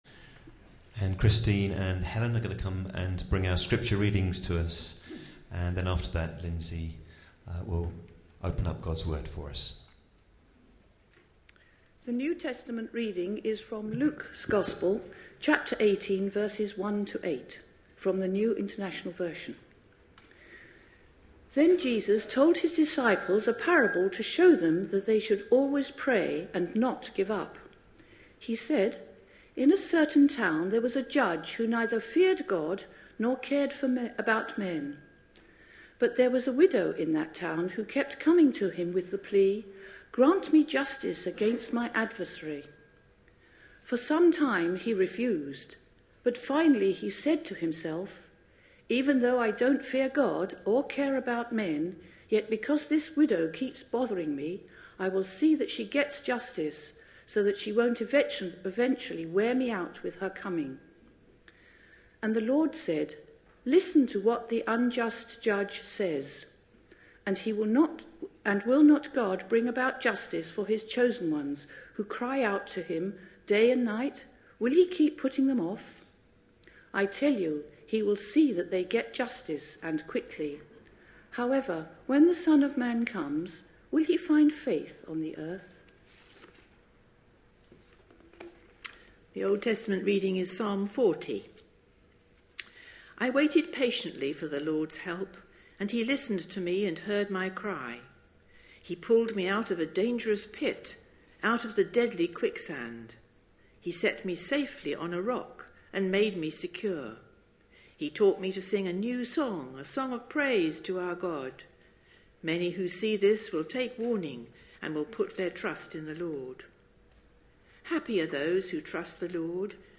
A sermon preached on 22nd October, 2017, as part of our Radishes & Royalty: Growing as a Child of the Heavenly King. series.